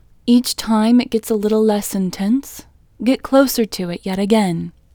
IN Technique First Way – Female English 18